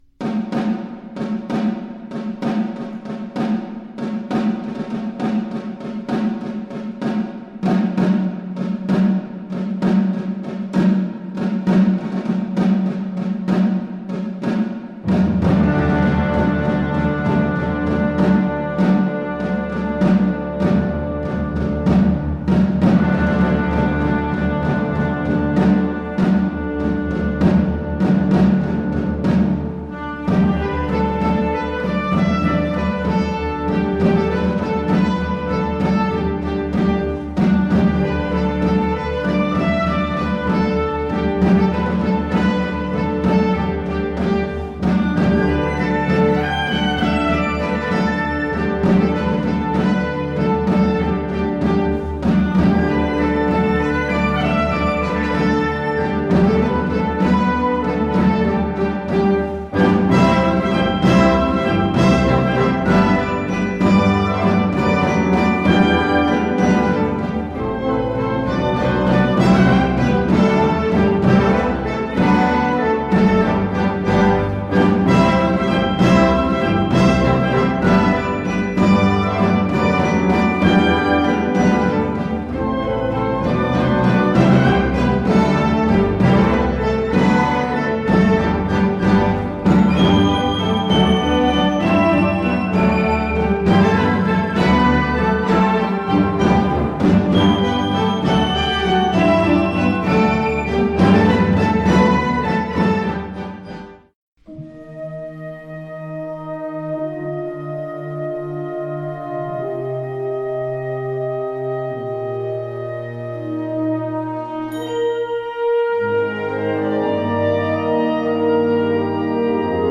Categoría Banda sinfónica/brass band
Subcategoría Suite
Instrumentación/orquestación Ha (banda de música)